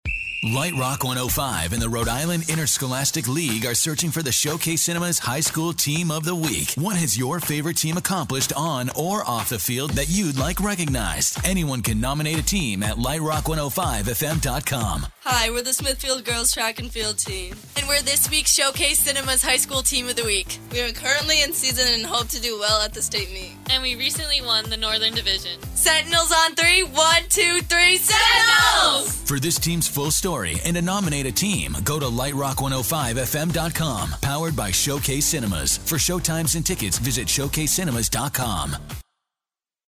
Lite Rock 105 On-Air Spot